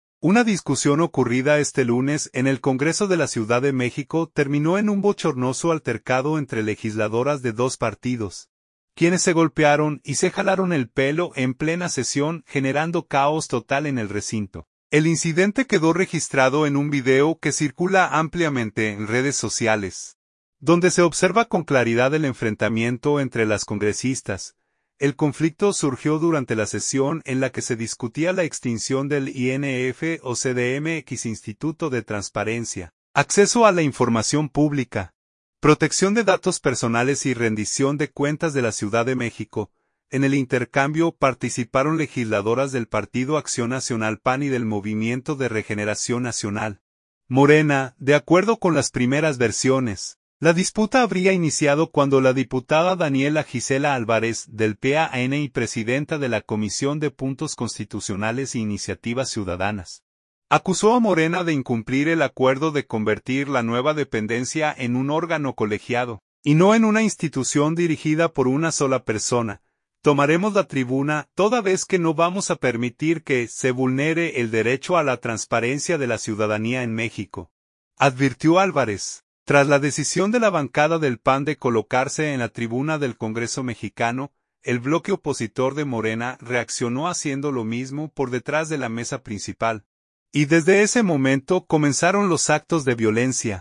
Caos en el Congreso de México: legisladoras se enfrentan a golpes y se jalan el pelo en plena sesión
Una discusión ocurrida este lunes en el Congreso de la Ciudad de México terminó en un bochornoso altercado entre legisladoras de dos partidos, quienes se golpearon y se jalaron el pelo en plena sesión, generando caos total en el recinto.